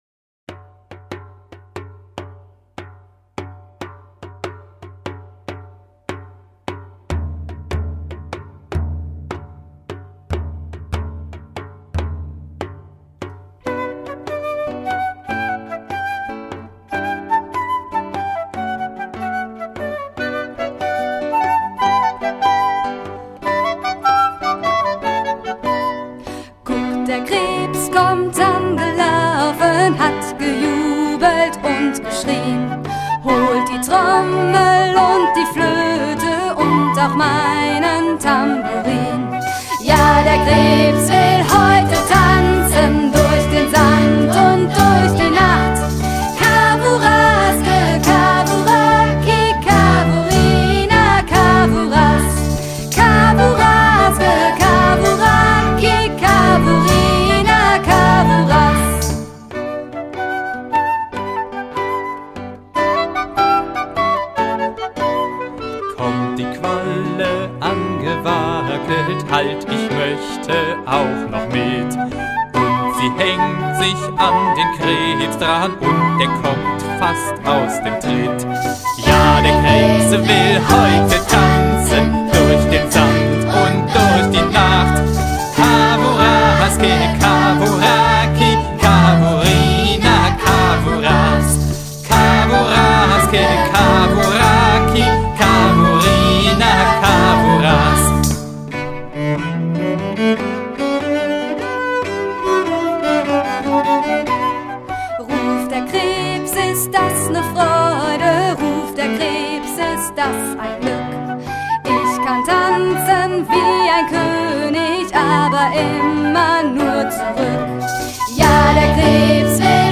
Lieder zum Spielen, Tanzen und Mitmachen